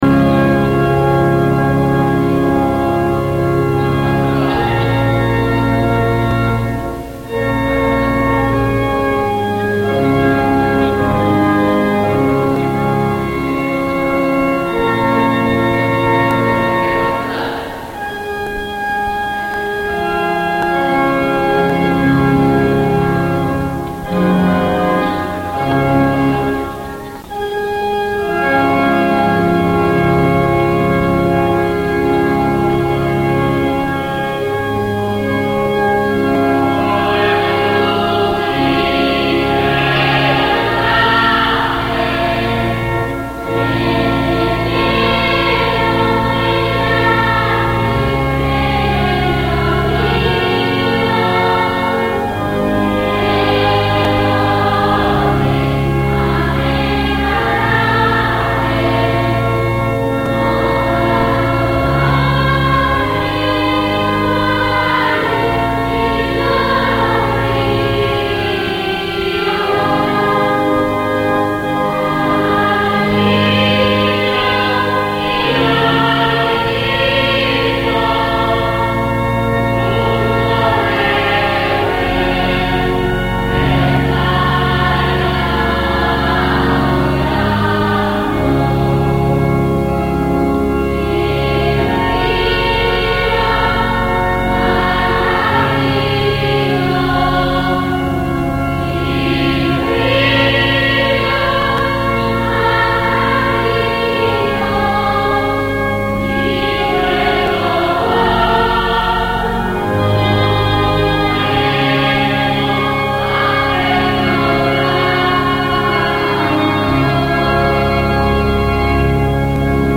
LA PROCESSIONE DEL VENERDI' SANTO A TERAMO
Canti solenni e struggenti risuonano nella notte, tra le vie cittadine, intonati dal corteo che porta in processione la statua della Vergine Addolorata trafitta da un pugnale.